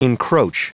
Prononciation du mot encroach en anglais (fichier audio)
Prononciation du mot : encroach